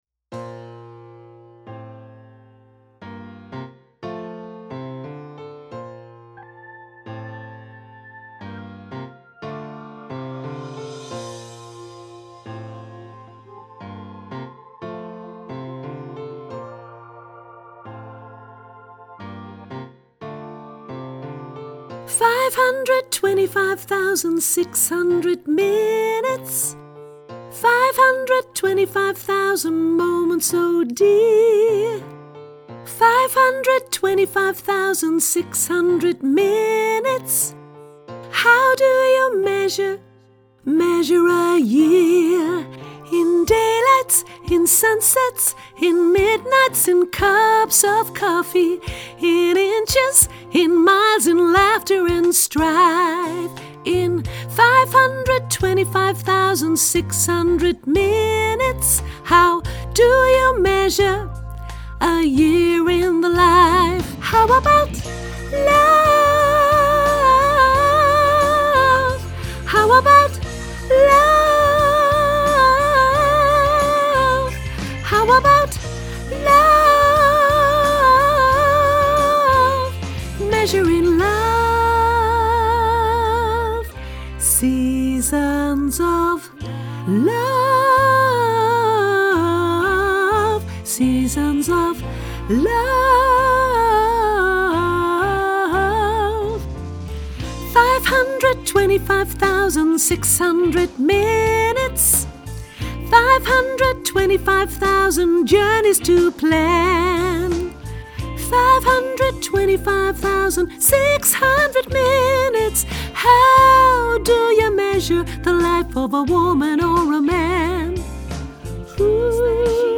sopraan hoog